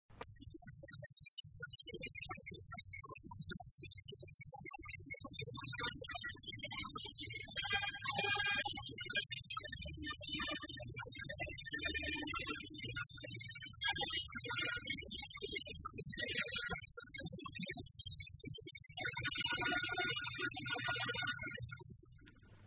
jingle 4 and